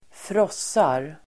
Uttal: [²fr'ås:ar]